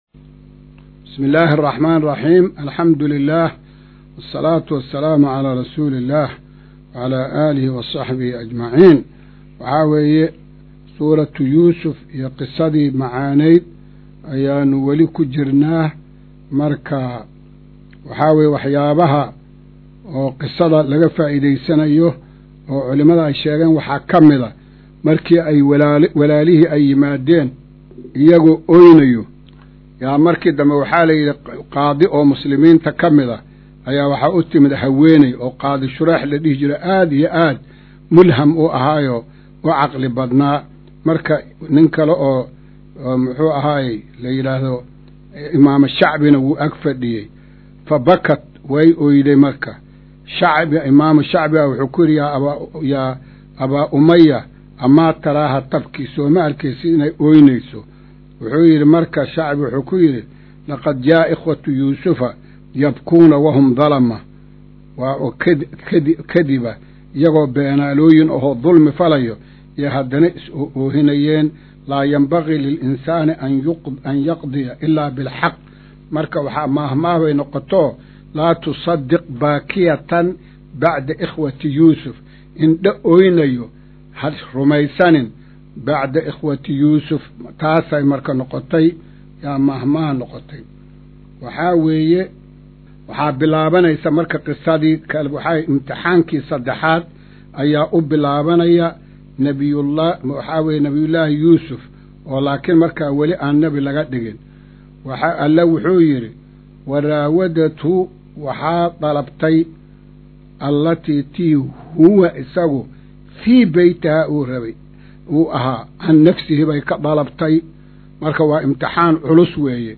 Maqal:- Casharka Tafsiirka Qur’aanka Idaacadda Himilo “Darsiga 118aad”